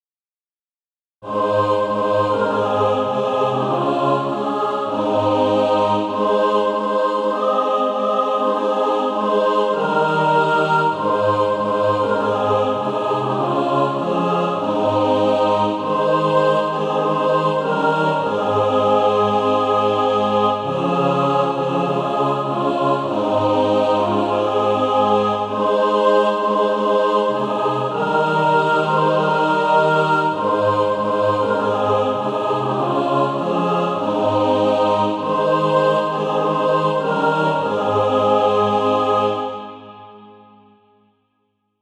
And also a mixed track to practice to
Practice then with the Chord quietly in the background.